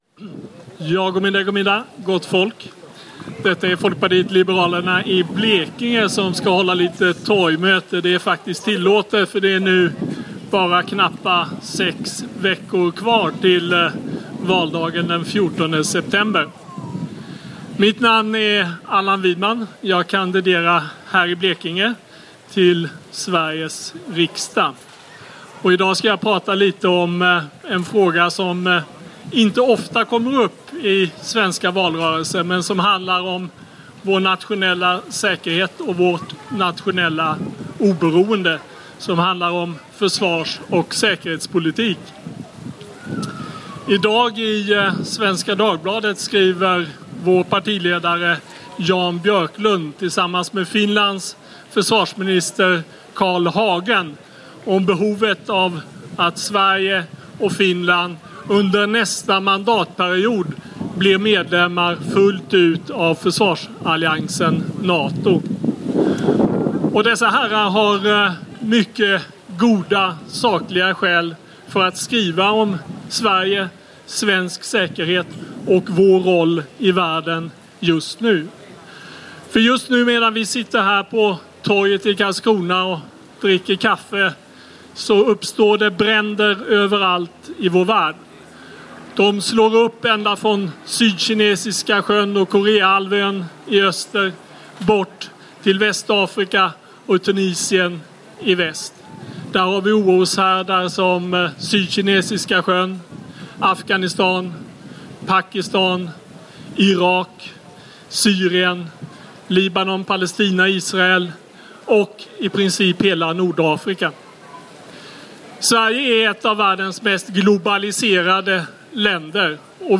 Allan Widman talar i Karlskrona 7 augusti 2014
Folkpartiets försvarspolitiska företrädare Allan Widman på Klaipeda-platsen i Karlskrona. Allan Widman förklarar varför FP är för en NATO-anslutning och talar också om försvarsfrågorna i och kring Östersjön.